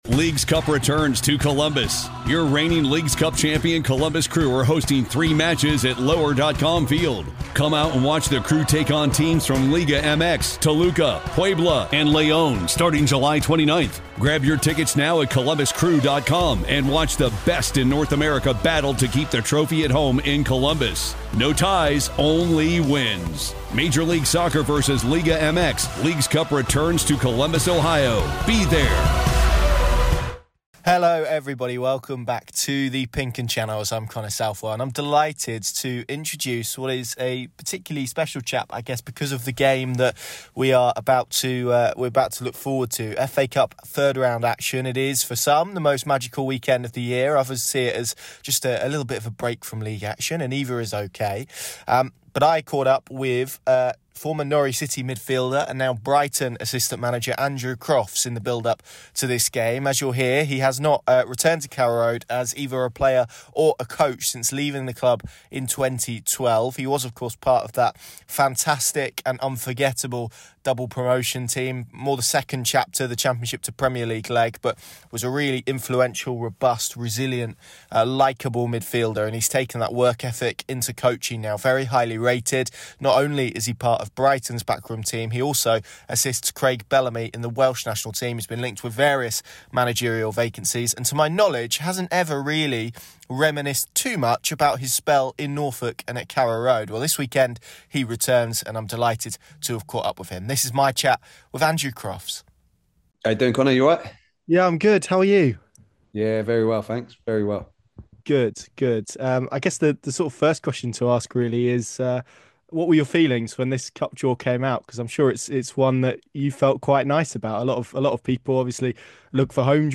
Andrew Crofts Interview | His memories ahead of a Carrow Road return this weekend | The Pink Un